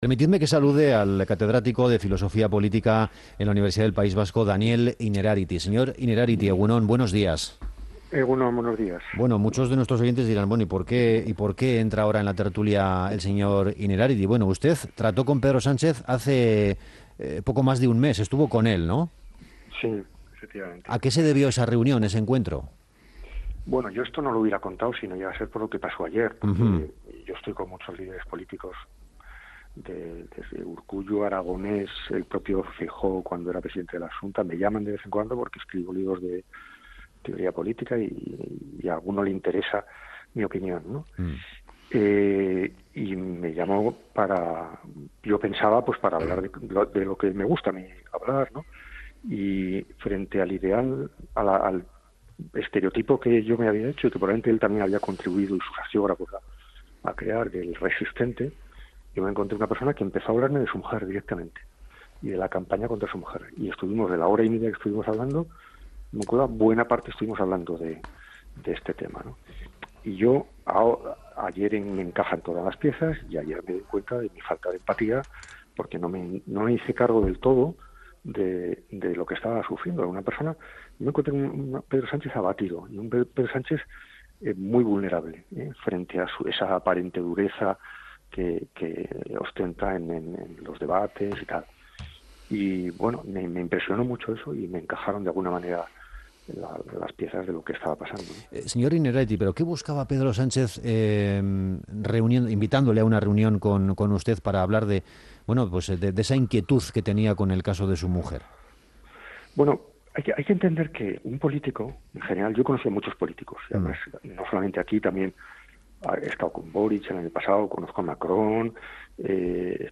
Entrevistado en ONDA VASCA, Innerarity ha reconocido que frente al estereotipo que tenía de Sánchez, se encontró a un hombre "abatido".